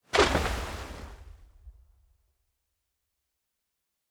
Water arrow trail3.wav